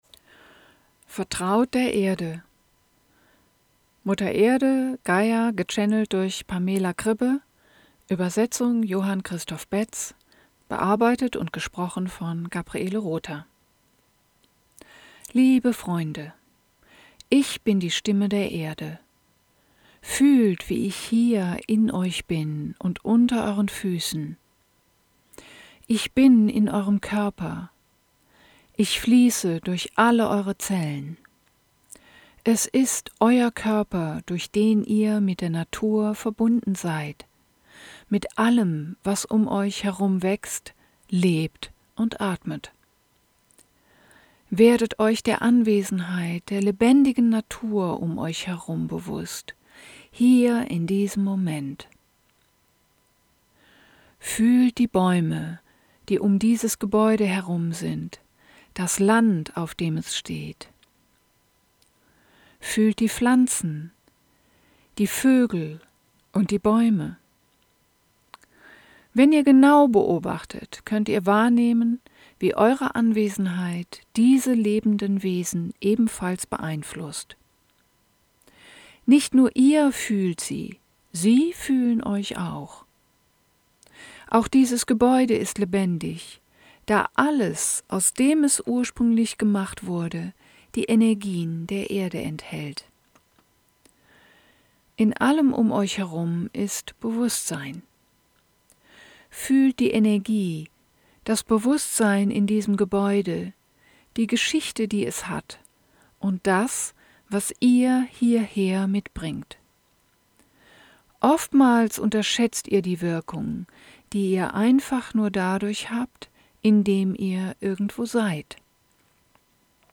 Channeling